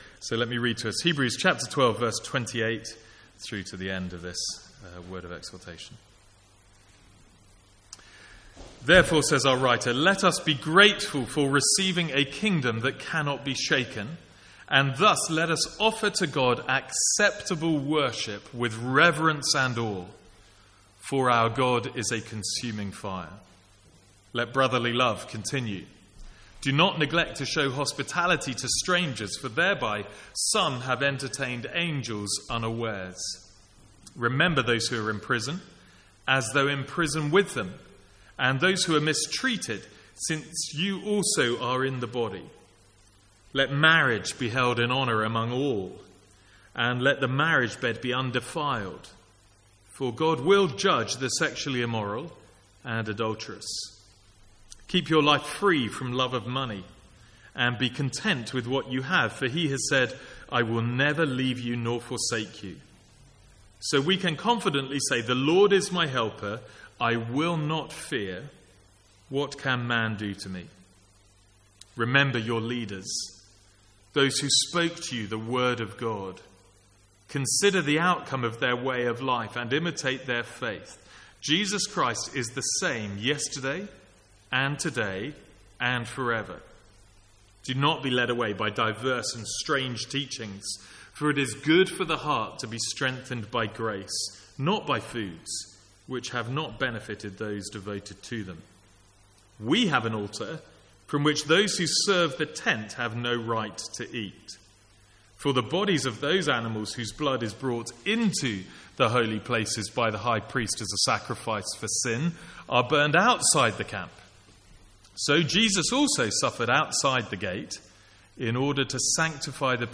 From the Sunday evening series in Hebrews.